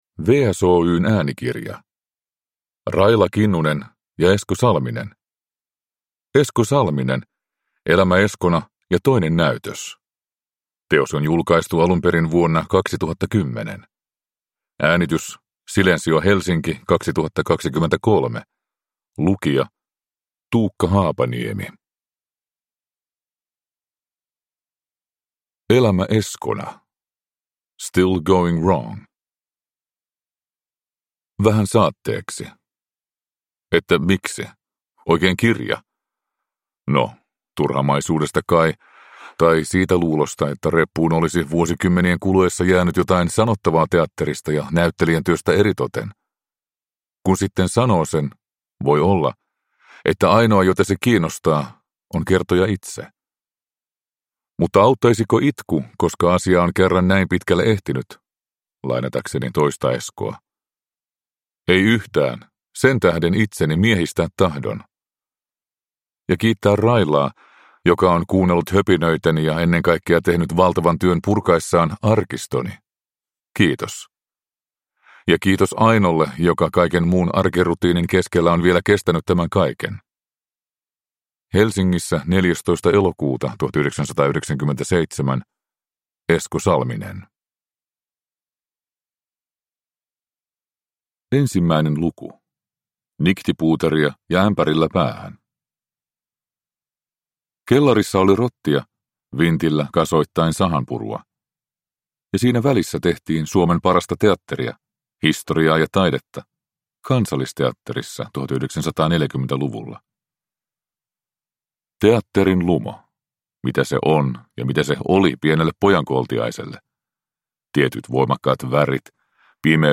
Esko Salminen (ljudbok) av Esko Salminen